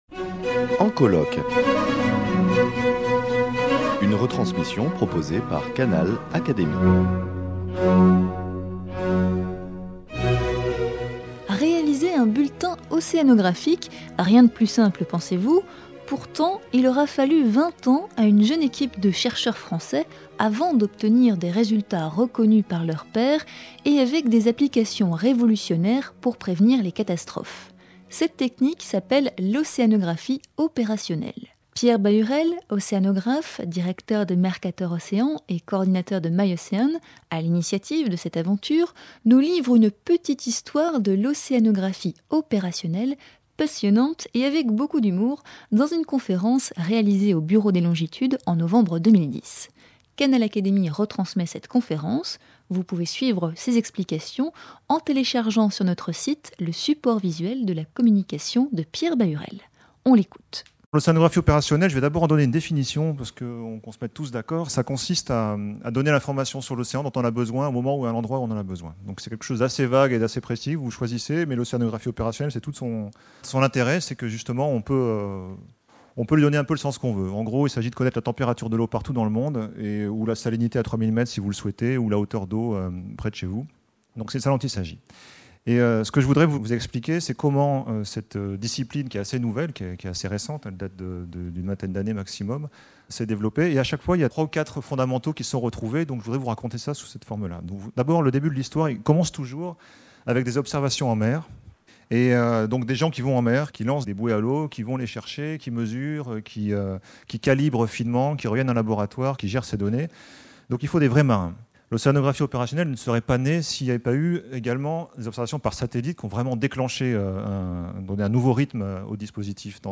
lors d’une conférence donnée au Bureau des longitudes en novembre 2010.